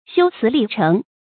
修辞立诚 xiū cí lì chéng 成语解释 写文章应表现出作者的真实意图。